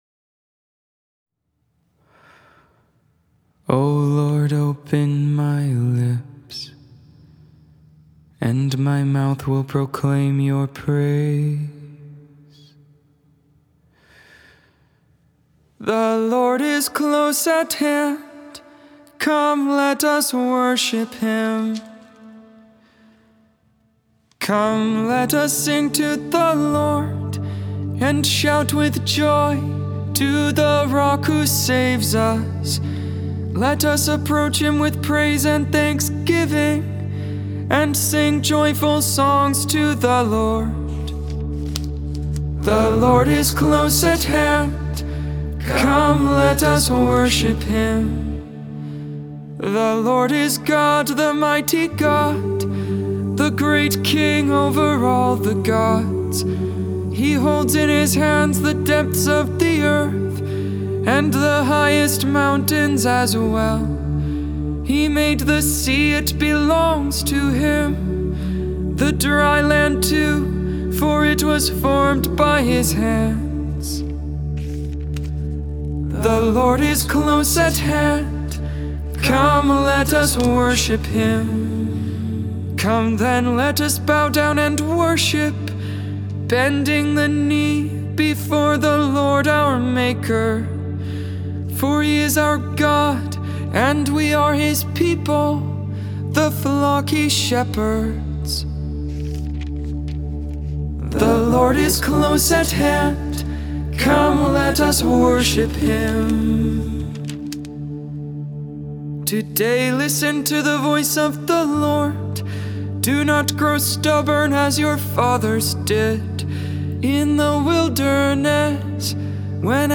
Benedictus (English, Tone 8, Luke 1v68-79) Intercessions: Come, Lord Jesus!